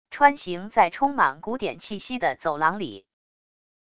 穿行在充满古典气息的走廊里wav音频